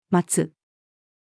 待つ-female.mp3